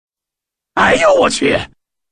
男声哎呦我去音效_人物音效音效配乐_免费素材下载_提案神器